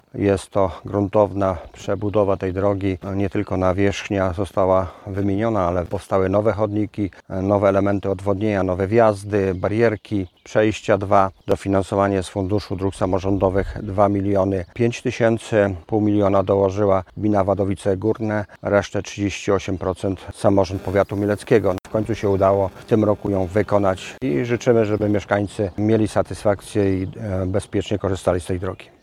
Mówił starosta mielecki Stanisław Lonczak.